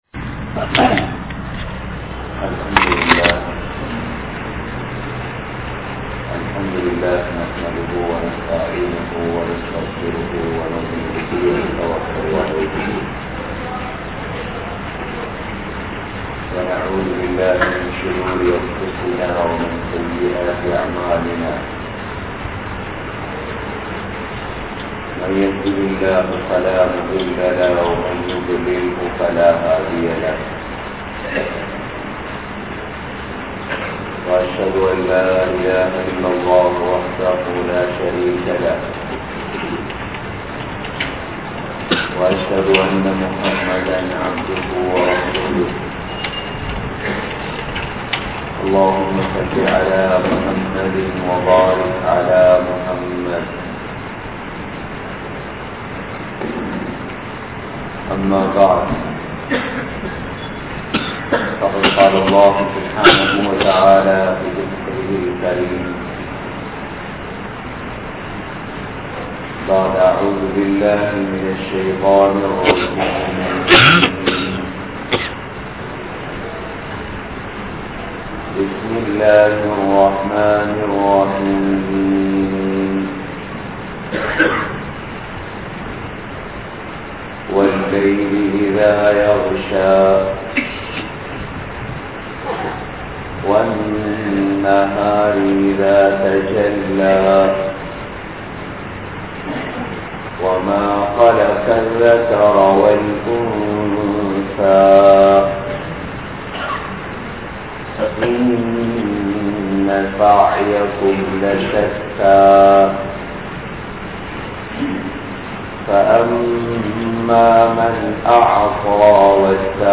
Ihlaas (இஹ்லாஸ்) | Audio Bayans | All Ceylon Muslim Youth Community | Addalaichenai